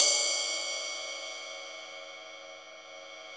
CYM XRIDE 3A.wav